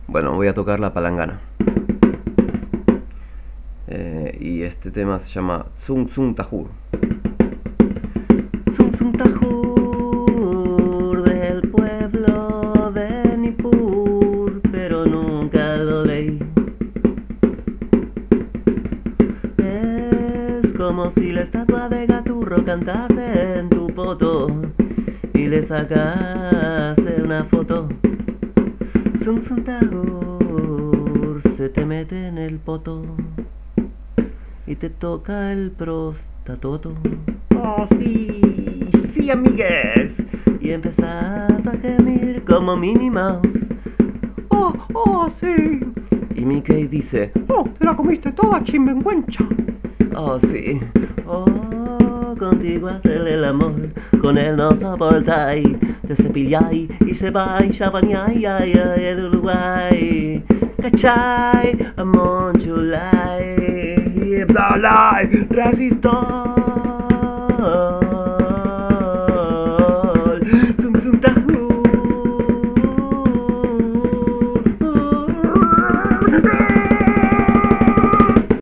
Acá quería cantar algo con una percusión y no tenía otra cosa que una pequeña palangana que compré para lavar la ropa con la que me sueno los mocos en invierno cuando me quedo sin papel de cocina.